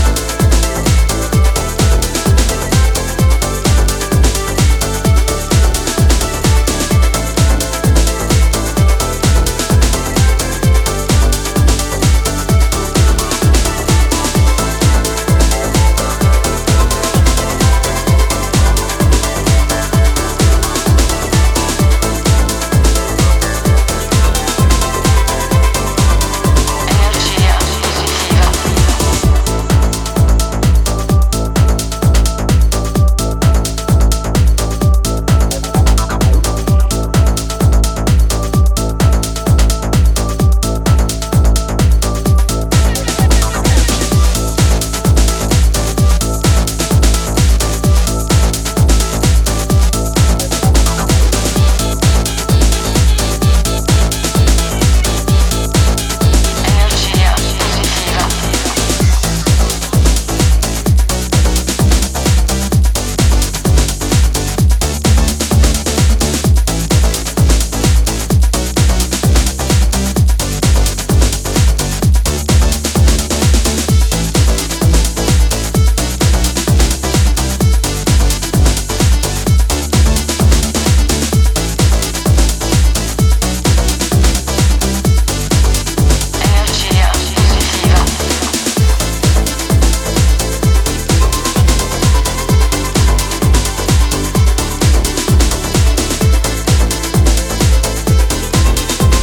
Original Mix